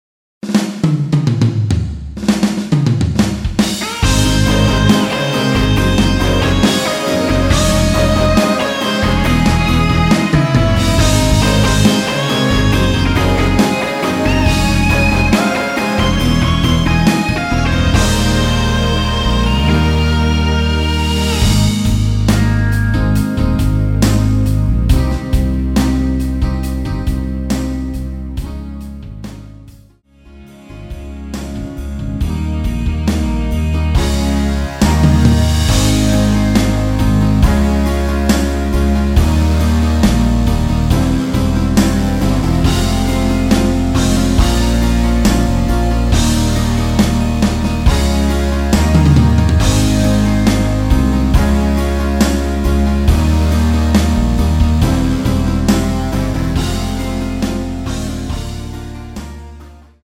MR 입니다.
Ab
◈ 곡명 옆 (-1)은 반음 내림, (+1)은 반음 올림 입니다.
앞부분30초, 뒷부분30초씩 편집해서 올려 드리고 있습니다.